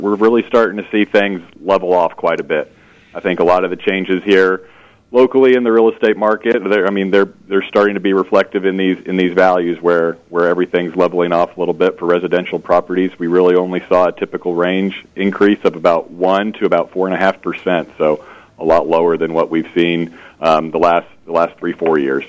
Janzen joined KVOE’s Morning Show this week, saying his staffers react to market activity.